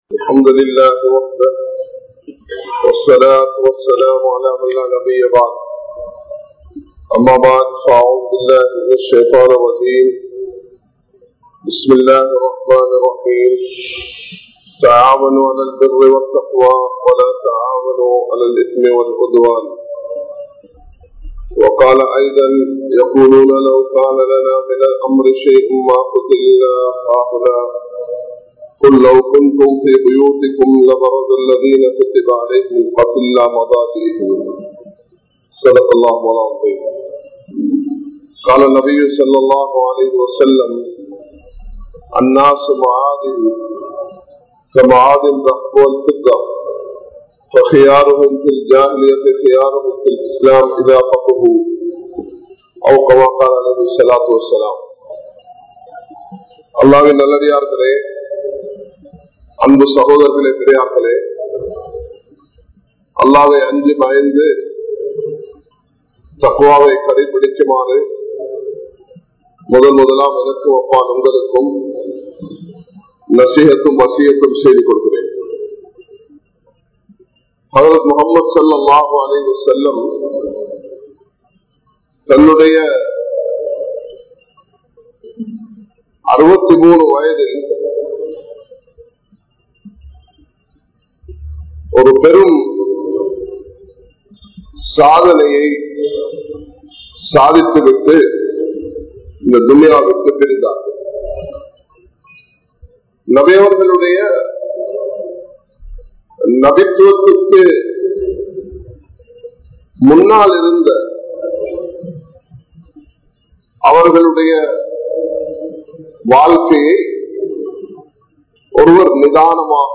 Maarka Kalvien Nanmaihal (மார்க்க கல்வியின் நன்மைகள்) | Audio Bayans | All Ceylon Muslim Youth Community | Addalaichenai
Colombo 04, Majma Ul Khairah Jumua Masjith (Nimal Road)